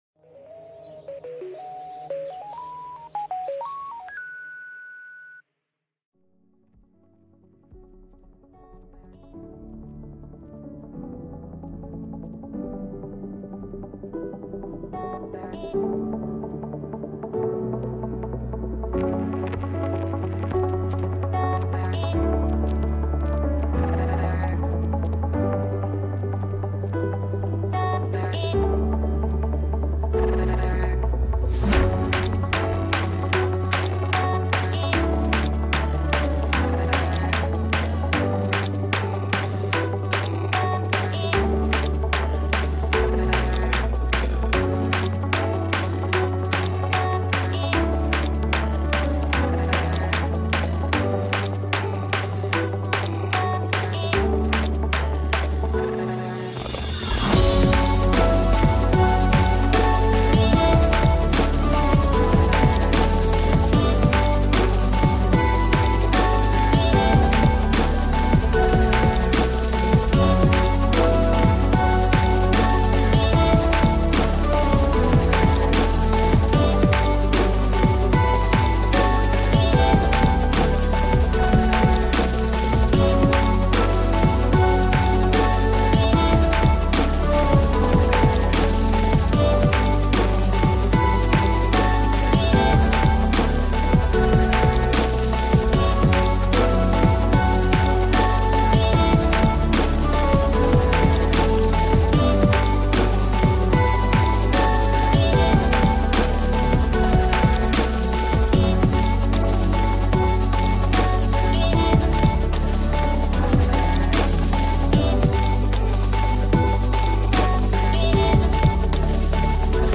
Future Bass